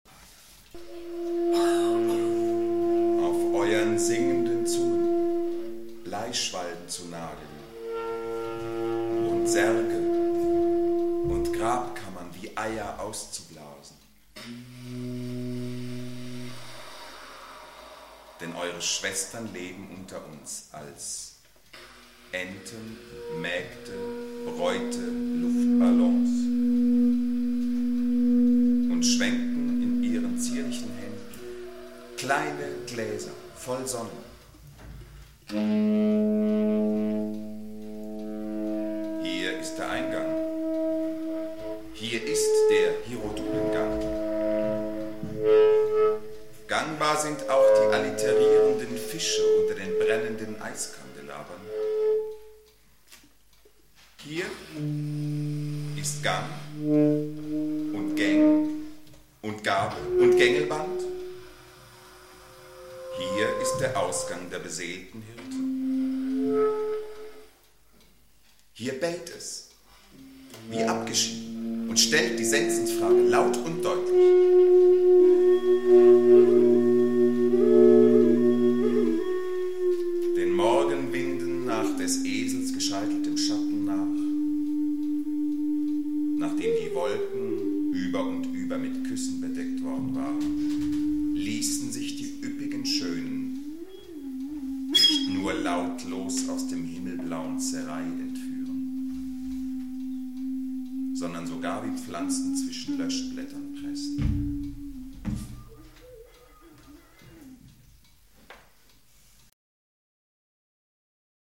live bei act art 9 am 19. Juni 1999 in Zirndorf.